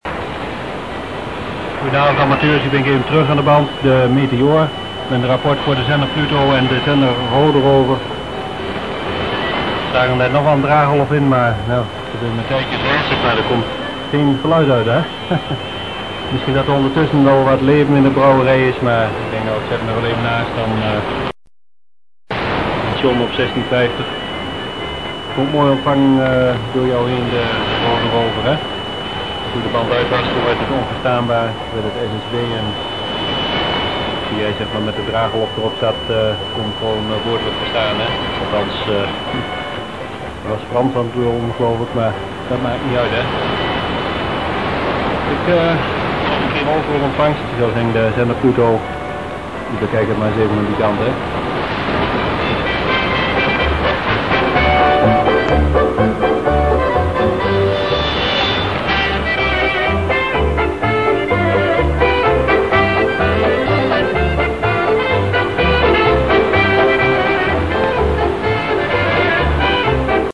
Mp3-clips of pirate radio stations which I have heard here:          / *= Heard in Paimio with 800 mtr L-wire